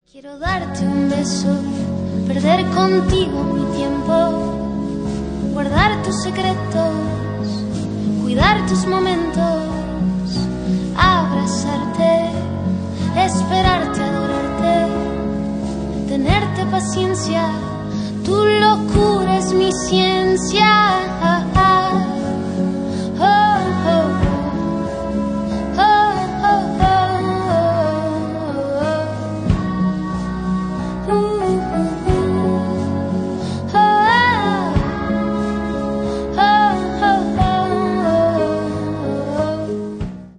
• Качество: 320, Stereo
поп
женский вокал
спокойные
медленные
indie pop